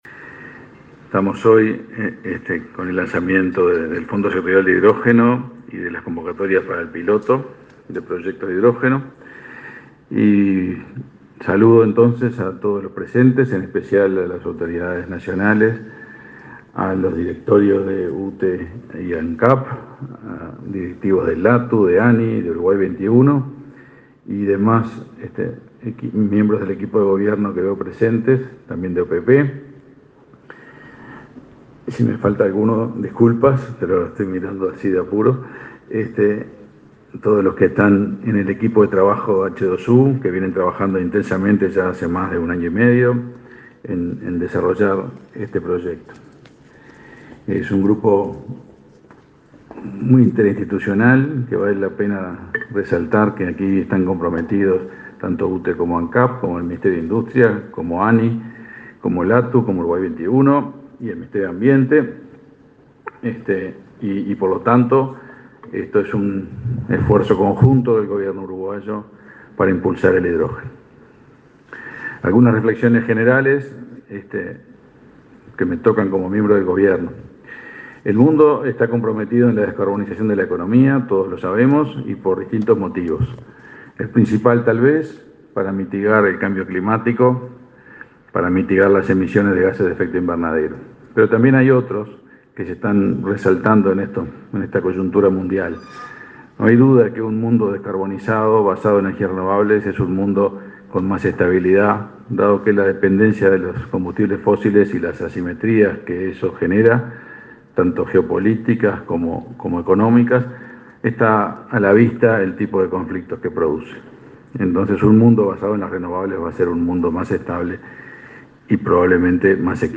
Fondo Sectorial de Hidrógeno 30/03/2022 Compartir Facebook X Copiar enlace WhatsApp LinkedIn El ministro de Industria, Omar Paganini; el director del Laboratorio Tecnológico del Uruguay (LATU), Gabriel Murara, y el presidente de la Agencia Nacional de Investigación e Innovación (ANII), Flavio Caiafa, participaron de la presentación del Fondo Sectorial de Hidrógeno, este miércoles 30 en la Torre de Antel.